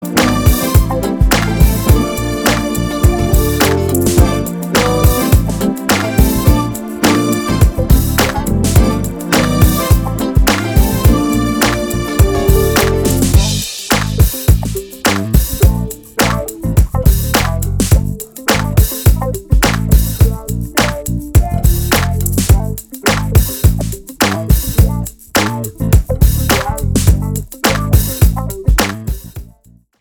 EASY LISTENING  (02.08)